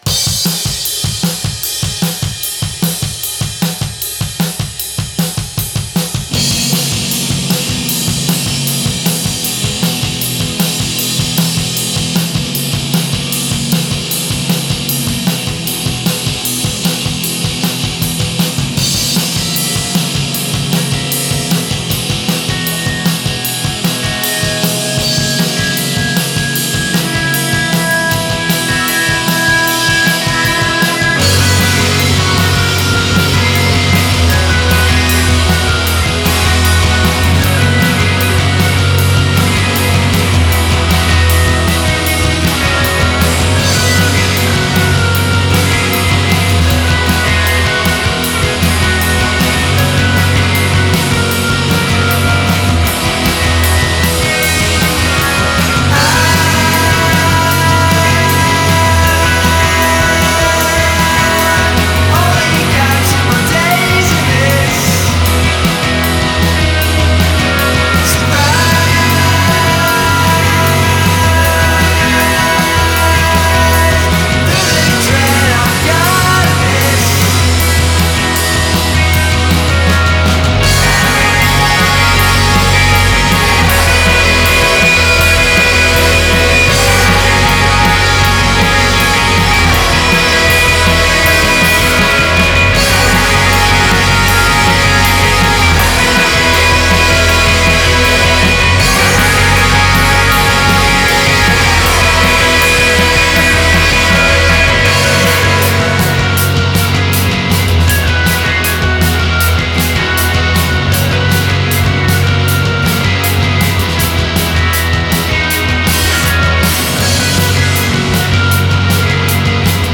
rock bruitiste limite garage